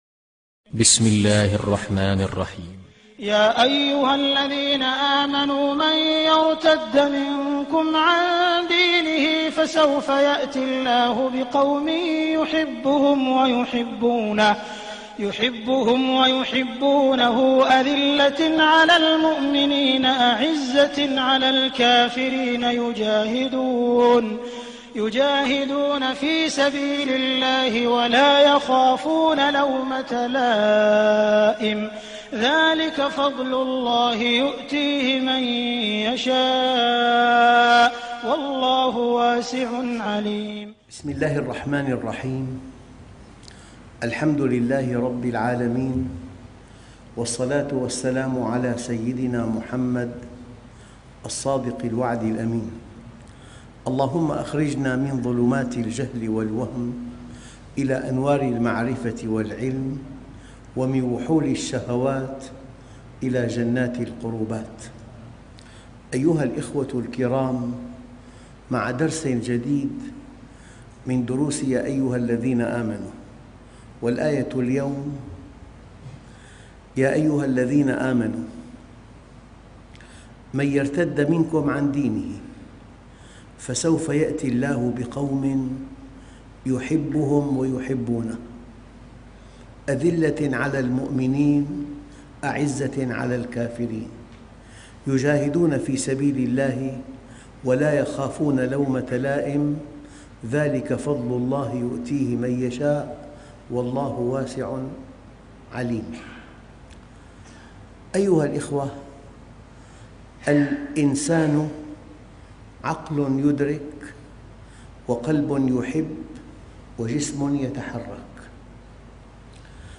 قوم يحبهم ويحبونه ...- دروس مسجد التقوى -عمان- الأردن - الشيخ محمد راتب النابلسي